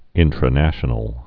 (ĭntrə-năshə-nəl, -năshnəl)